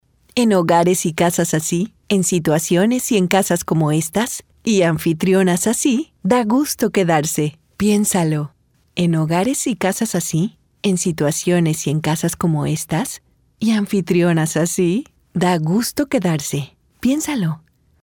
Mexican female voice over. locutor